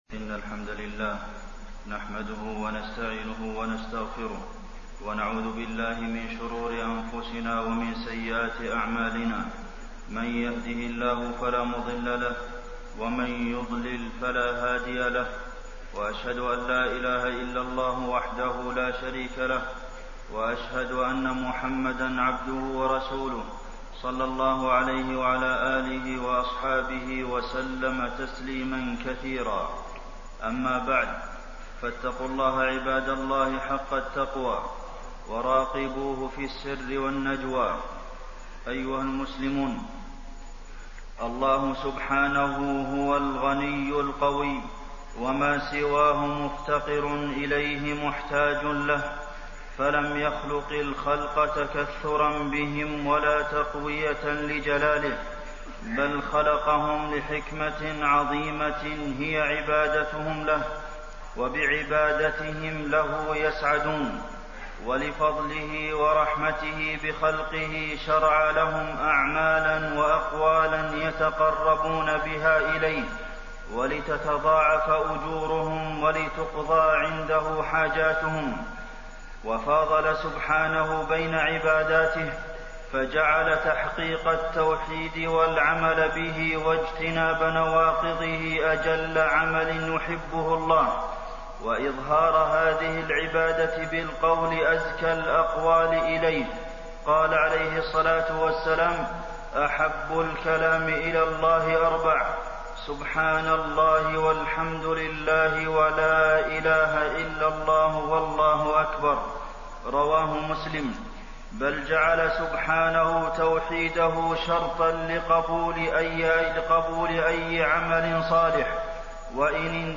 تاريخ النشر ١ ذو الحجة ١٤٣٢ هـ المكان: المسجد النبوي الشيخ: فضيلة الشيخ د. عبدالمحسن بن محمد القاسم فضيلة الشيخ د. عبدالمحسن بن محمد القاسم الحج وفضائله